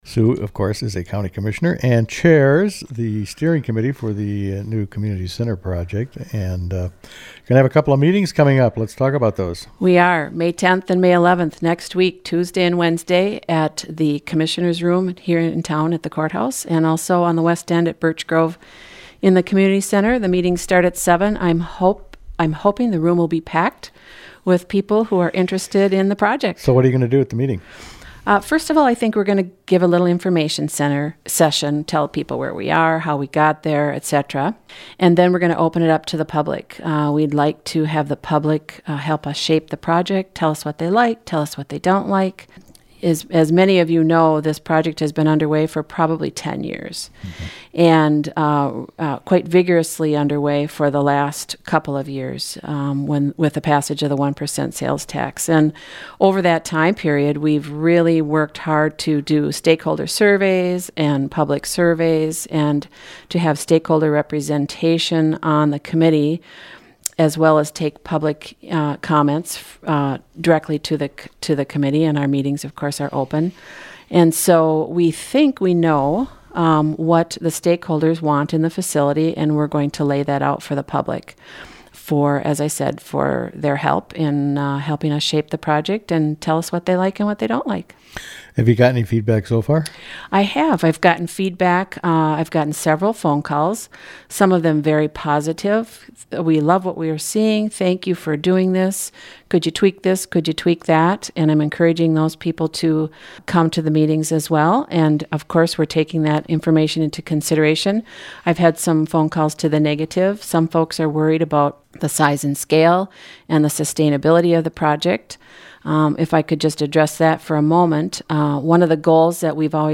Sue Hakes.mp3